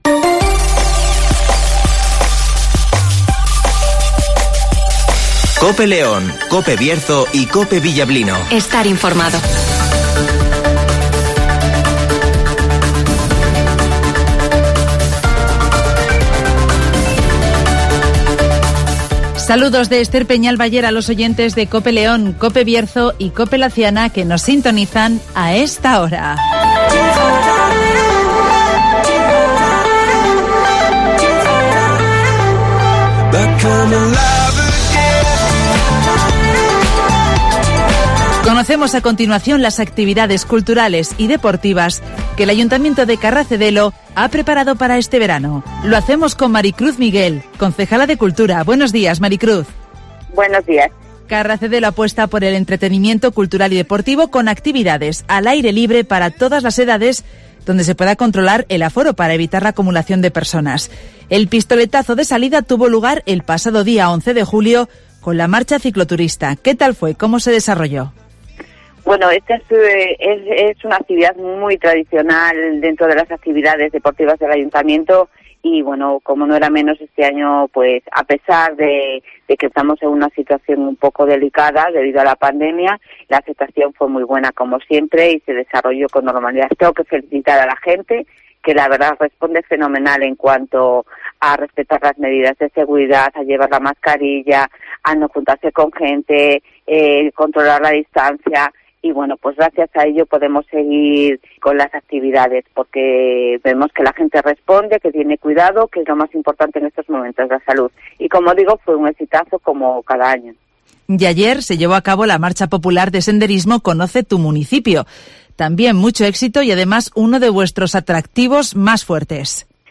Conocemos las actividades culturales y deportivas del Ayuntamiento de Carracedelo para este verano (Entrevista